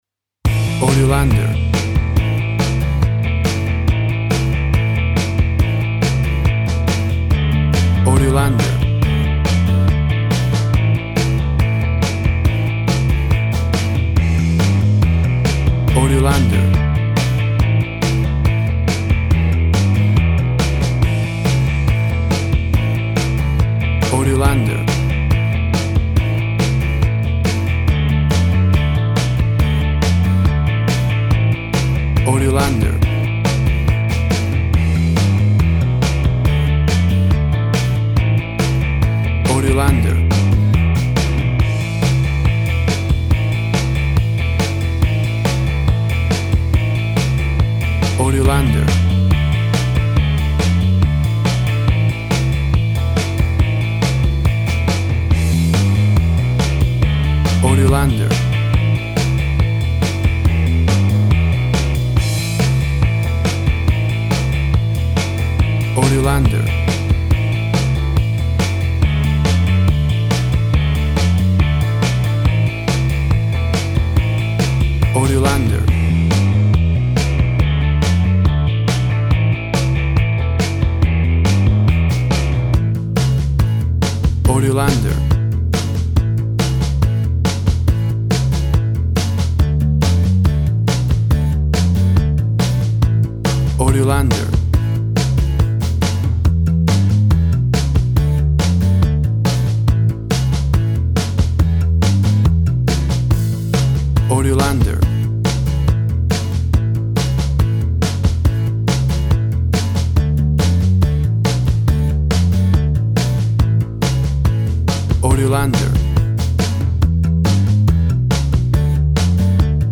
WAV Sample Rate 16-Bit Stereo, 44.1 kHz
Tempo (BPM) 142